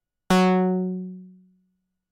标签： midivelocity96 F4 midinote66 YamahaCS30L synthesizer singlenote multisample
声道立体声